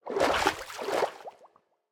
snapshot / assets / minecraft / sounds / liquid / swim15.ogg
swim15.ogg